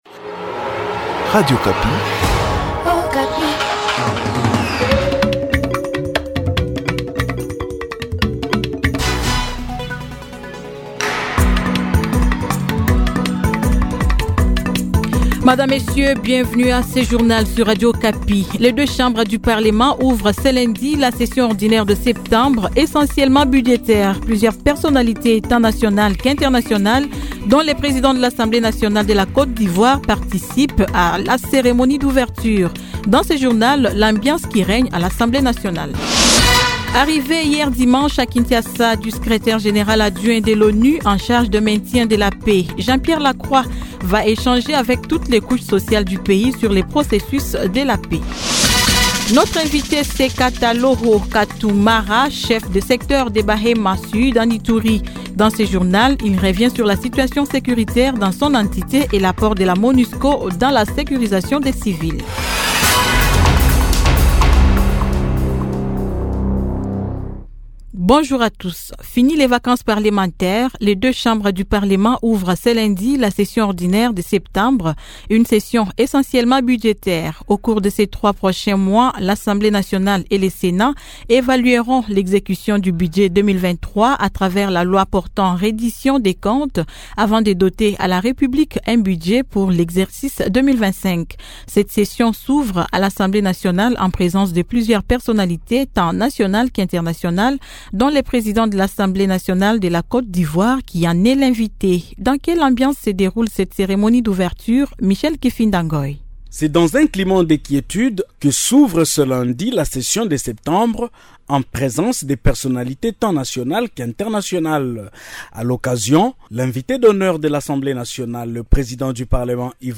Journal Midi